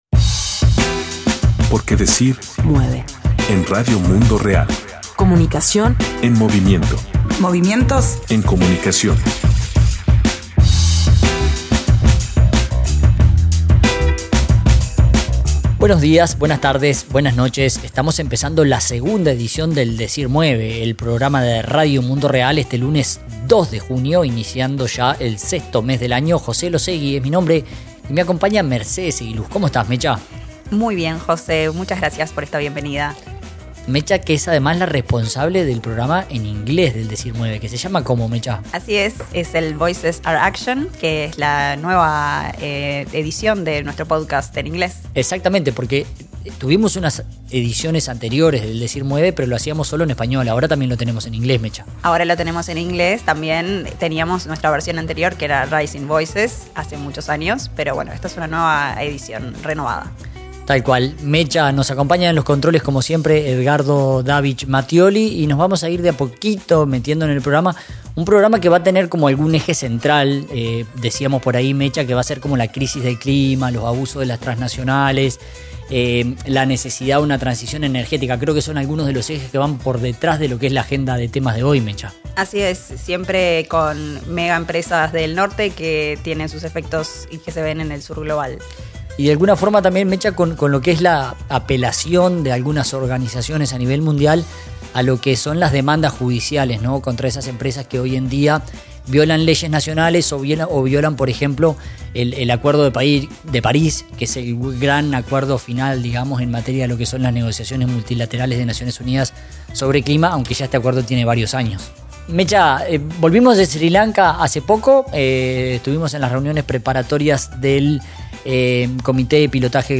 Con el adelanto de que encontrarán audios y reportes especiales desde los países, estos son los titulares: